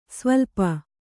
♪ svaḷa